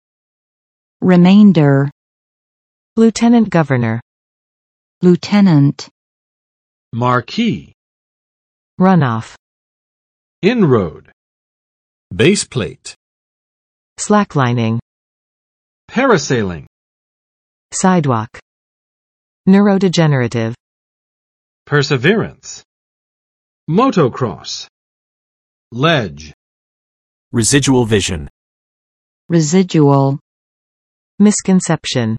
[rɪˋmendɚ] n. 余项